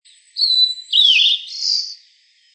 16-1阿里山藪鳥duet1.mp3
黃痣藪鶥 Liocichla steerii
嘉義縣 阿里山 阿里山
錄音環境 森林
行為描述 二重唱
收音: 廠牌 Sennheiser 型號 ME 67